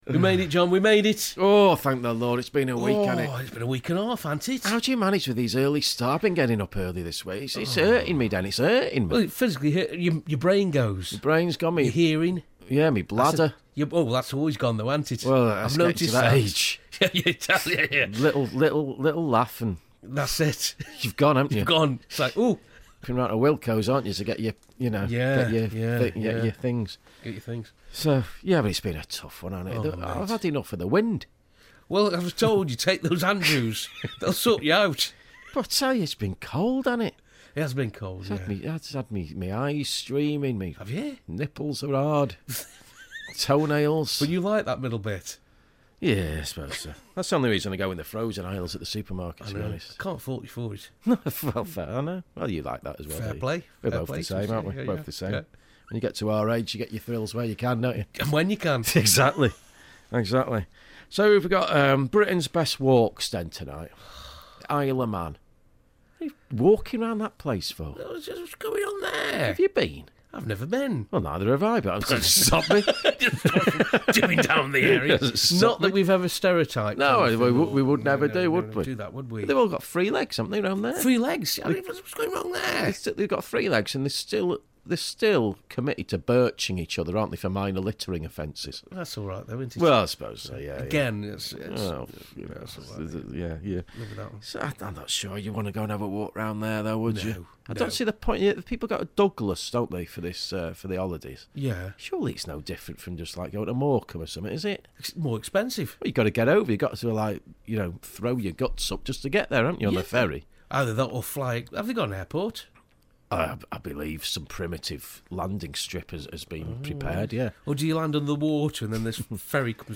Inane chattering from two bewildered old fools